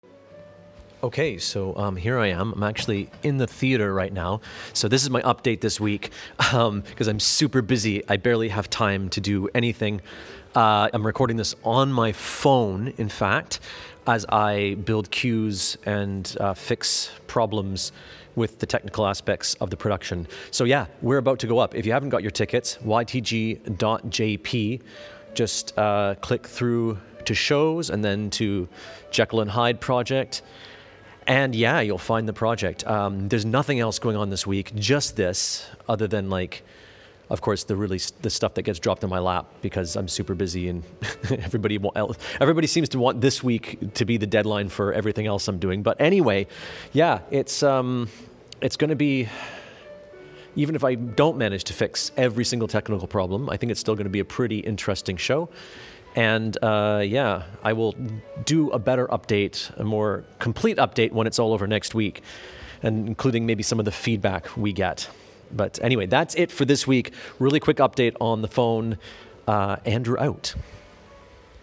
Quick update posted from my phone.